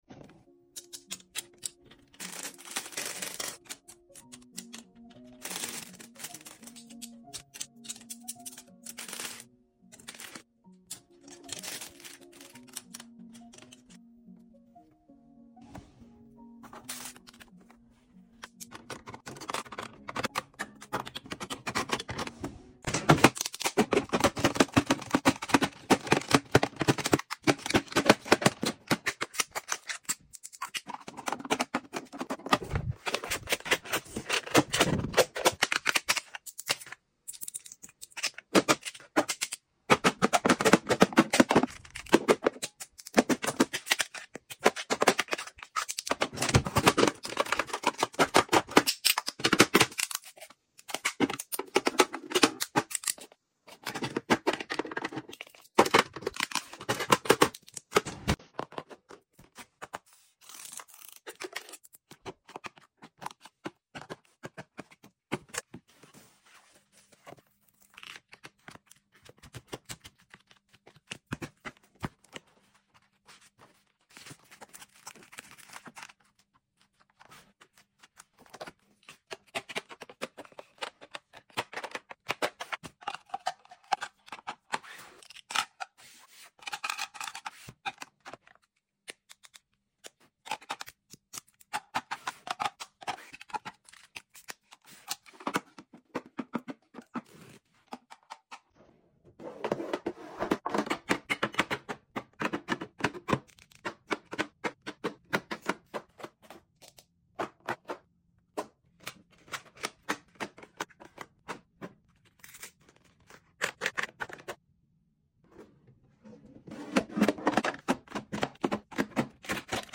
Beauty room organizing and restocking sound effects free download